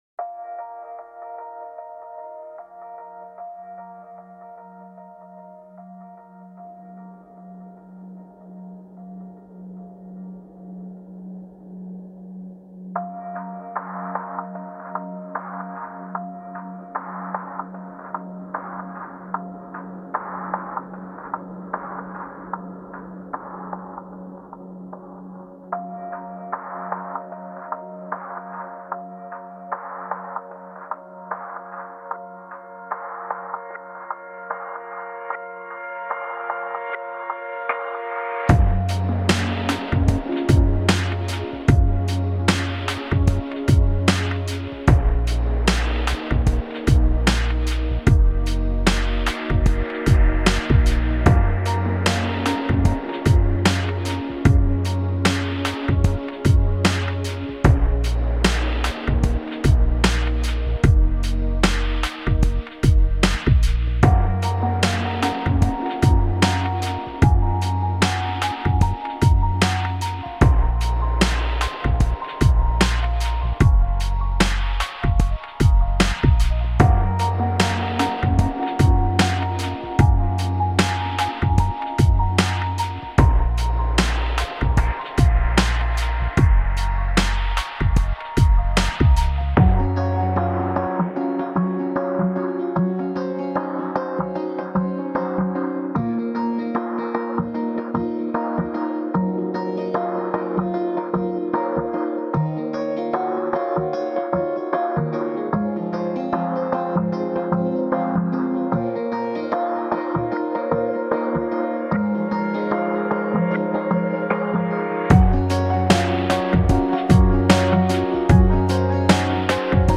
موسیقی امبینت موسیقی الکترونیک موسیقی آرامش بخش